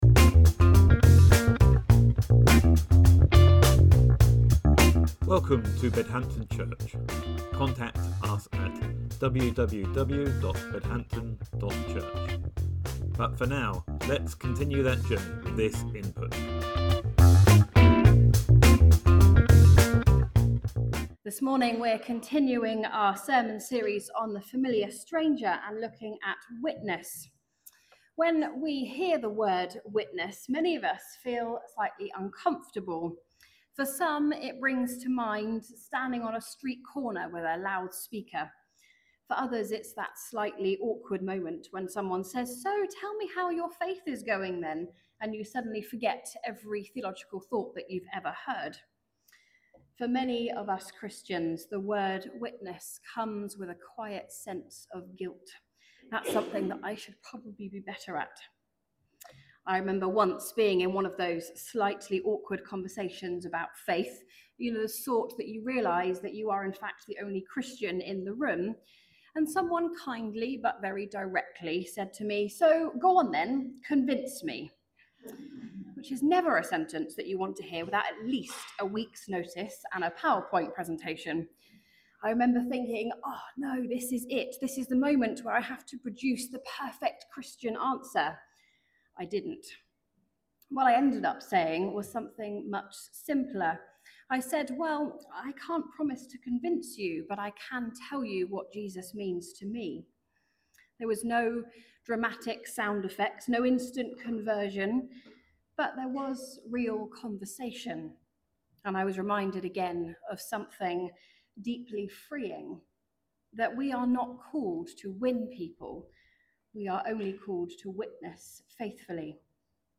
In The Familiar Stranger sermon series, we reflect upon his book as Tyler Staton reintroduces this oft-neglected Person of the Trinity, tracing the story of the Holy Spirit as it unfolds throughout the Bible, and inviting believers to close the gap between what Scripture reveals about the Holy Spirit and their lived experience.